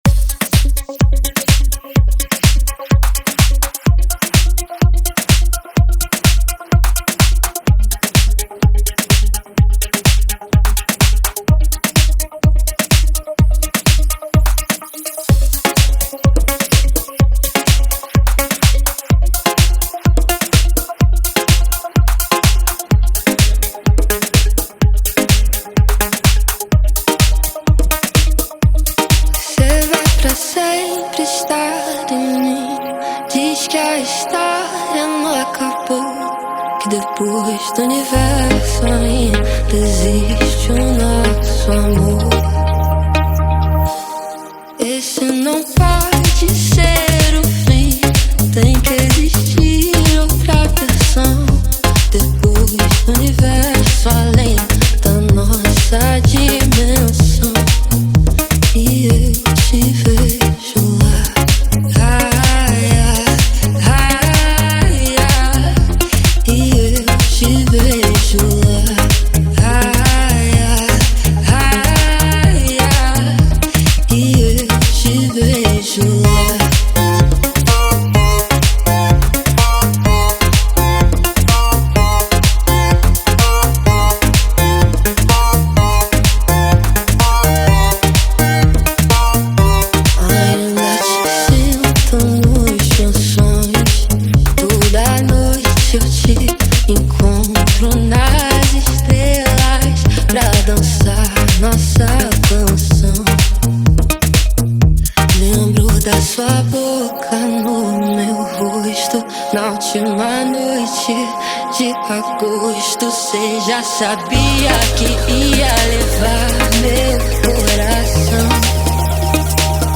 2025-03-12 10:32:48 Gênero: Funk Views